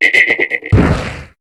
Cri de Tritox dans Pokémon HOME.